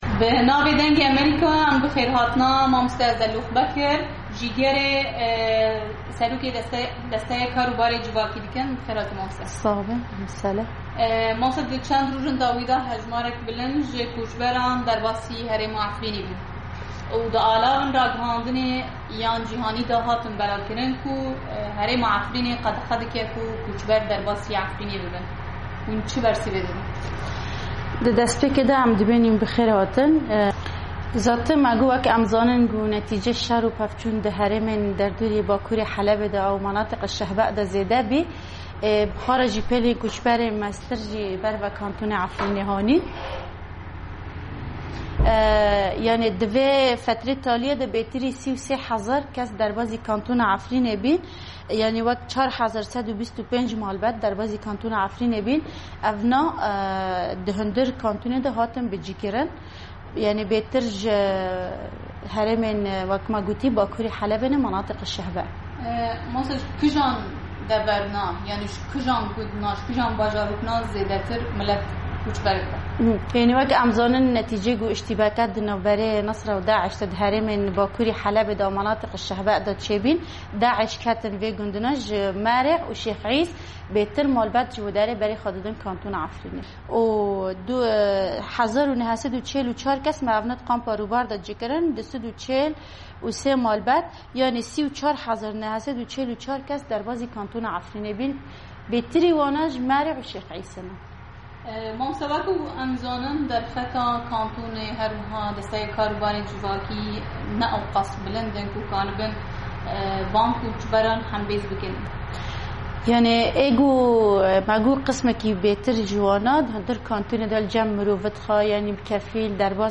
Raport bi deng 06_04_16